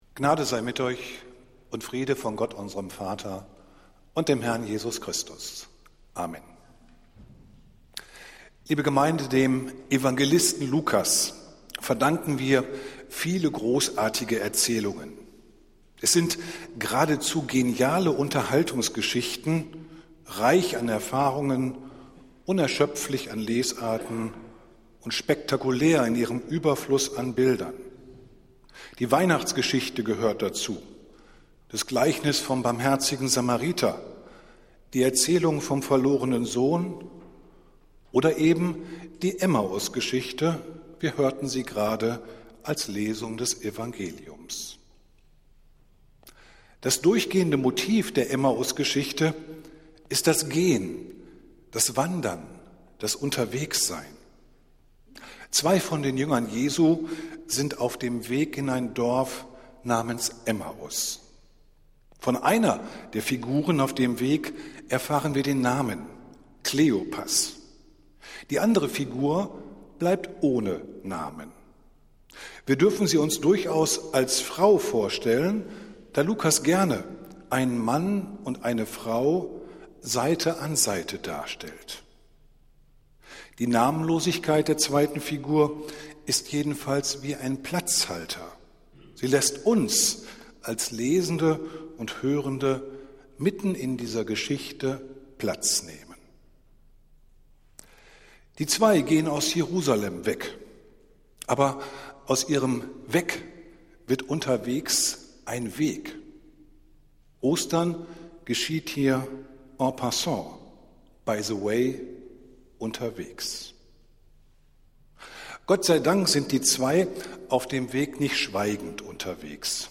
Predigt des Gottesdienstes aus der Zionskirche vom Ostermontag, 10.04.2023